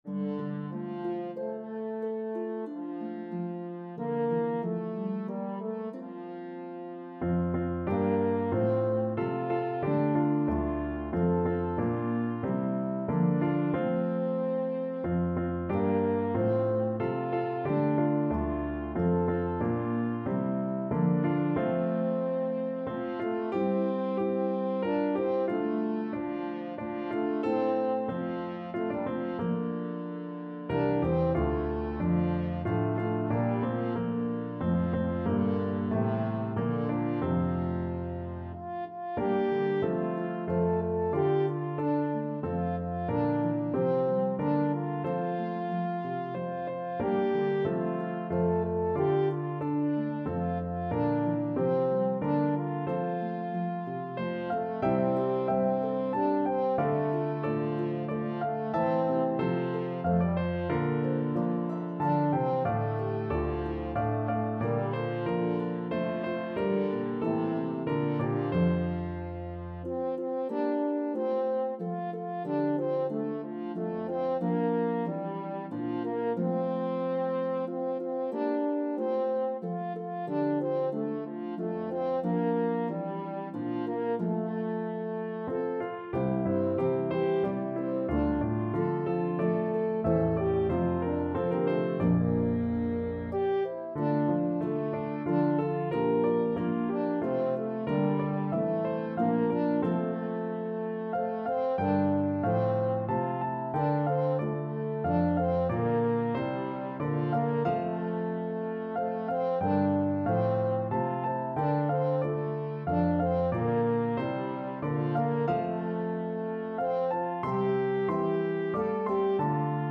A meditative trio arrangement
is a pentatonic hymn tune